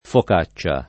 vai all'elenco alfabetico delle voci ingrandisci il carattere 100% rimpicciolisci il carattere stampa invia tramite posta elettronica codividi su Facebook focaccia [ fok #©© a ] s. f.; pl. ‑ce — sim. il pers. m. stor.